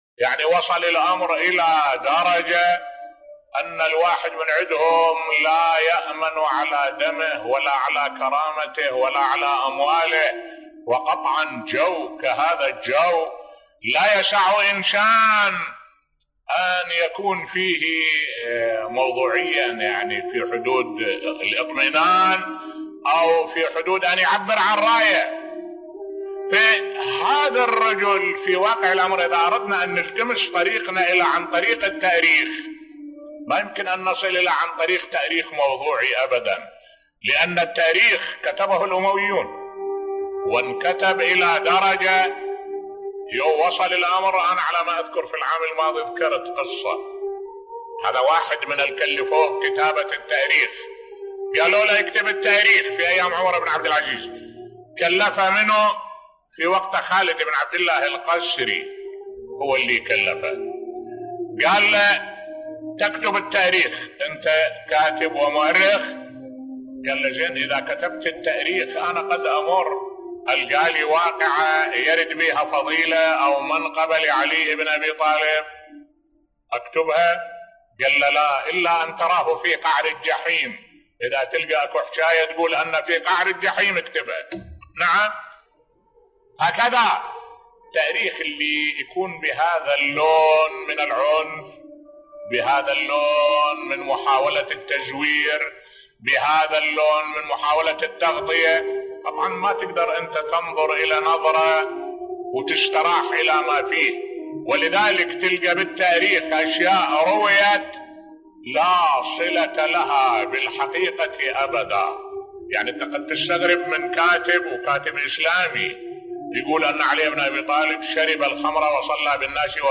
ملف صوتی كيف كتب التاريخ بصوت الشيخ الدكتور أحمد الوائلي